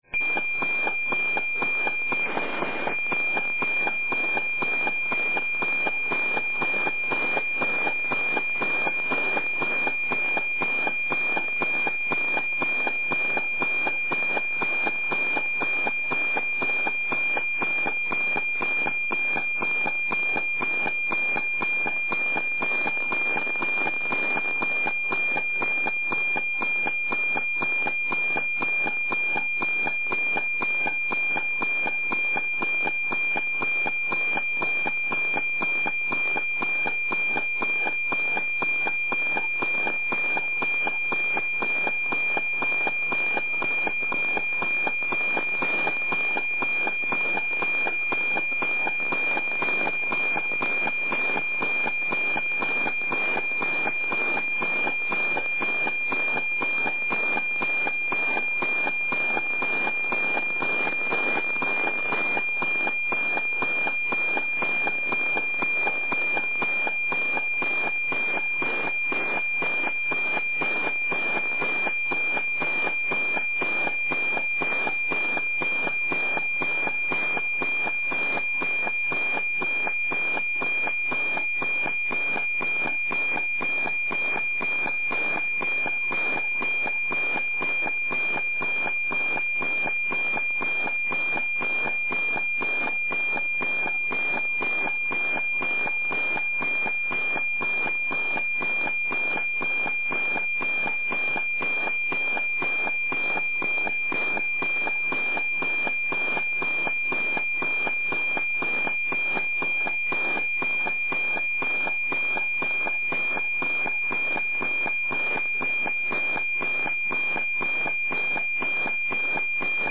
NOAA-17 APT Satellite Meteo Freq. 137.620 Mhz
I satelliti NOAA sono a bassa orbita mediamente viaggiano ad un altezza di 800 km circa (come il satellite Radioamatoriale Oscar-51). Il tempo di acquisizione e di circa 10 minuti, i segnali arrivano a terra fortissimi toccando punte di S'9.